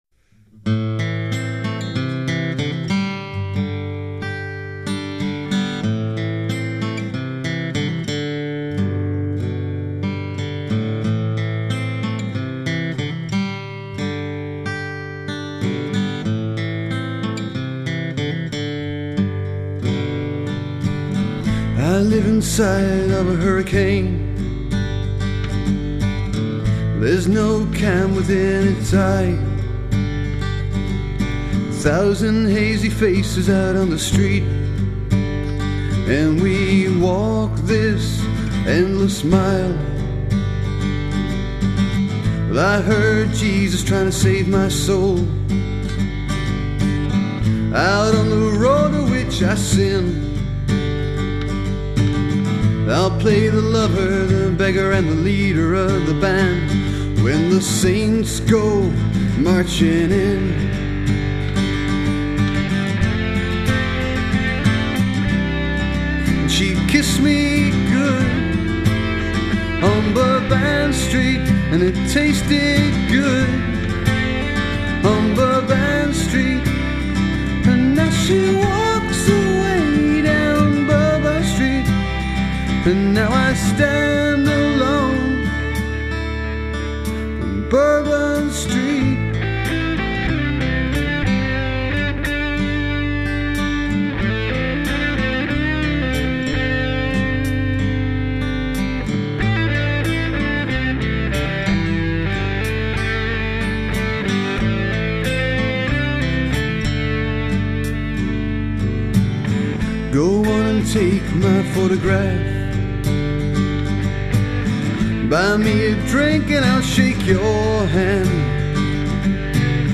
live version